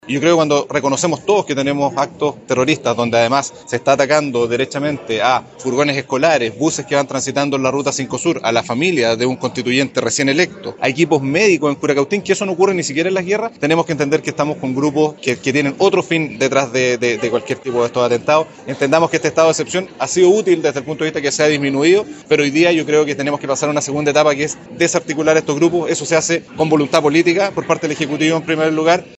El gobernador regional de La Araucanía, Luciano Rivas, dijo que cuando las autoridades coinciden en la presencia de actos terroristas en la zona, debe existir voluntad política para combatirlos.